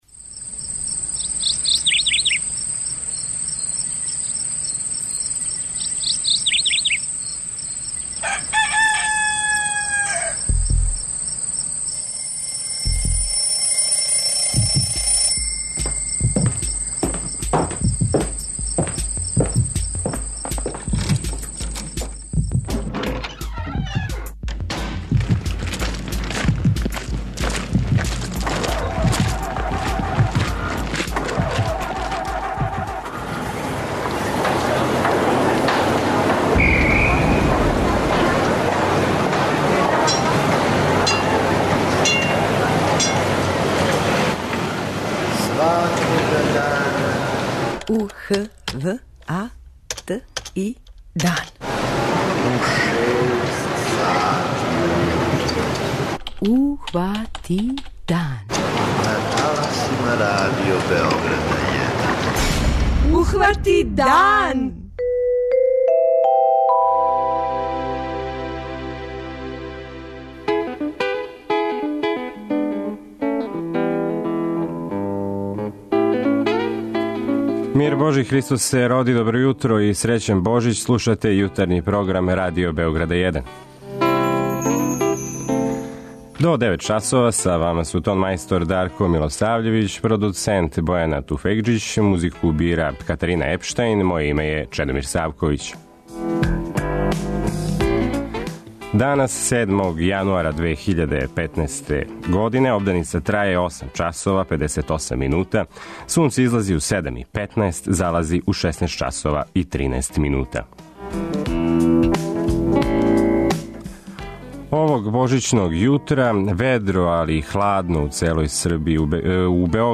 У оквиру емисије емитујемо: 06:03 Јутарњи дневник; 06:35 Догодило се на данашњи дан; 07:00 Вести; 07:05 Добро јутро децо; 08:00 Вести; 08:10 Српски на српском